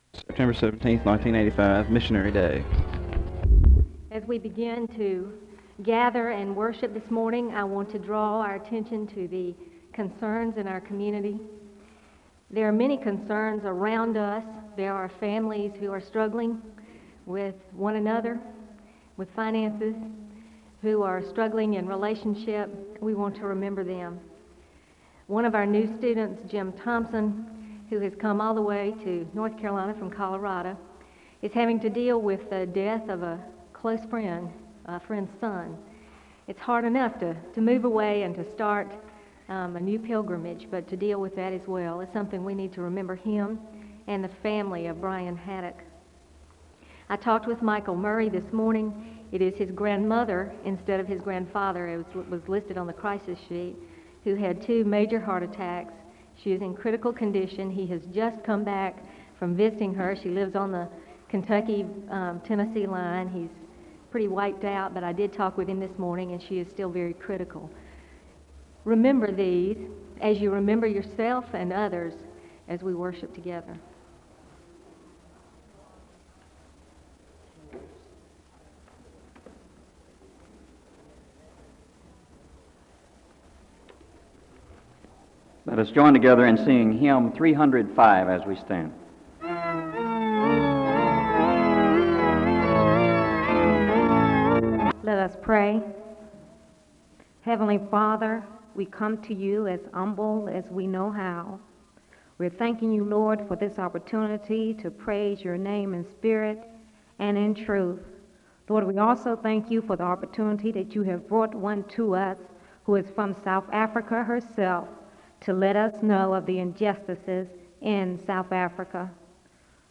The service begins with announcements over community concerns, and the speaker gives a word of prayer (00:00-02:35).